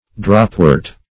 Search Result for " dropwort" : The Collaborative International Dictionary of English v.0.48: Dropwort \Drop"wort`\ (dr[o^]p"w[^u]rt`), n. (Bot.) An Old World species of Spir[ae]a ( Spir[ae]a filipendula ), with finely cut leaves.